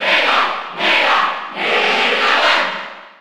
Category:Crowd cheers (SSB4) You cannot overwrite this file.
Mega_Man_Cheer_Spanish_PAL_SSB4.ogg